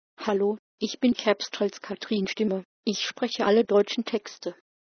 Sprecher für das Vorleseprogramm MWS Reader